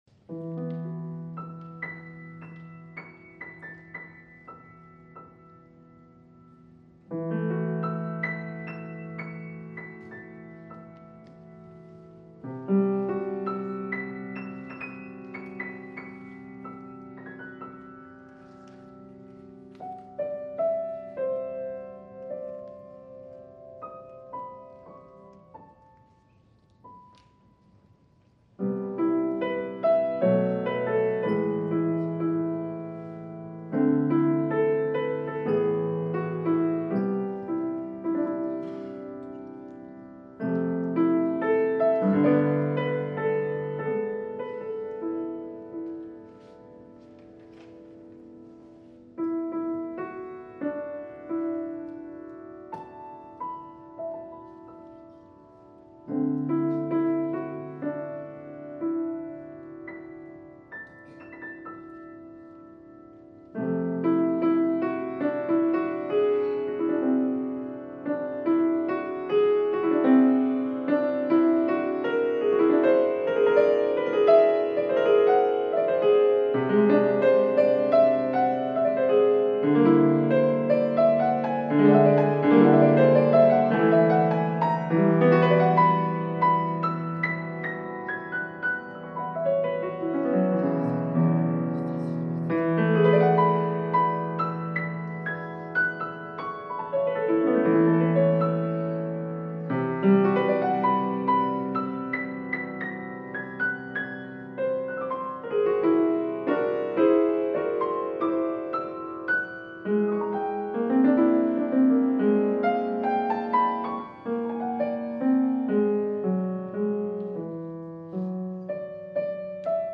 4-частная сюита для фортепиано:
Импрессионизм
Запись произведена на концерте по композиции